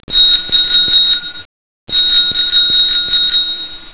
Cŕŕn Zvonček z bicykla 0:04